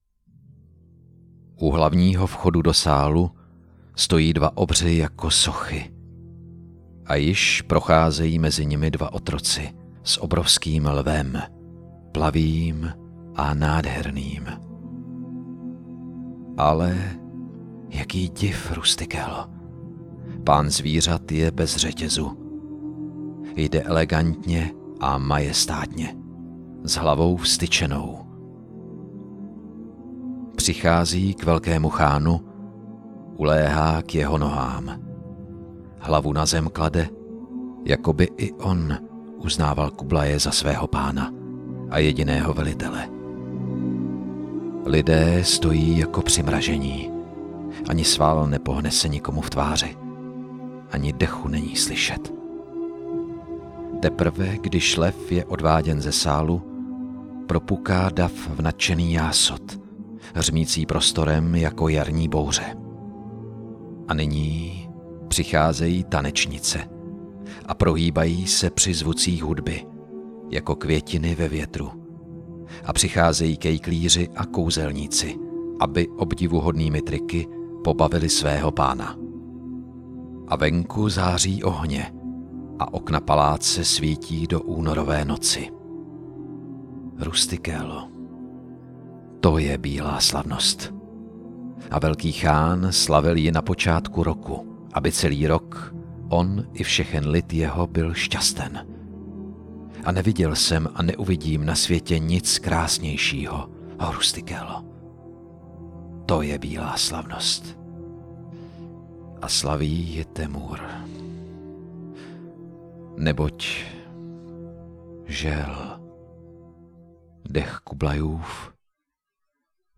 Ukázka z knihy
Audio vyprávění je podbarveno jemnou hudbou Ennia Morriconeho, která jen umocňuje samotný prožitek a autenticitu děje. Sám interpret příběhem žije.